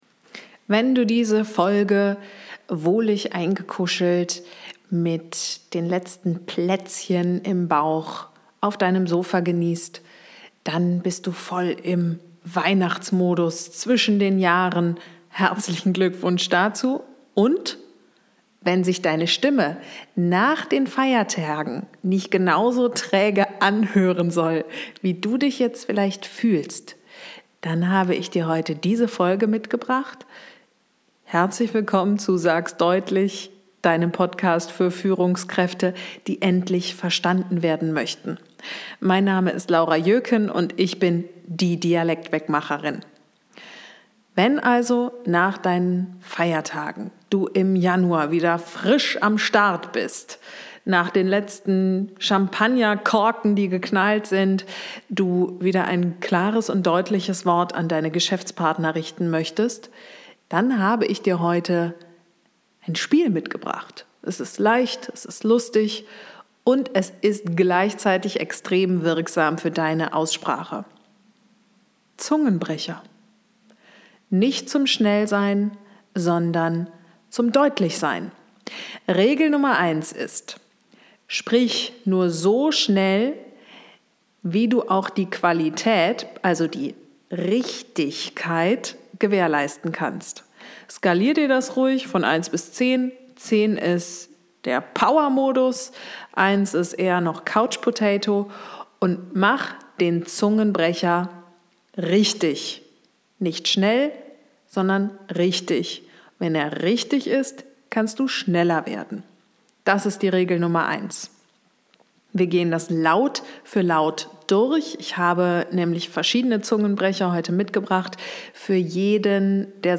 Ein spielerisches Warm-up für klares Hochdeutsch. Zungenbrecher nicht zum Schnellsprechen, sondern zum Deutlichsprechen. In dieser Folge trainierst du typische Problemlaute, die im Alltag gern verschwimmen.